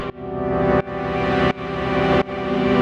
GnS_Pad-MiscA1:2_170-C.wav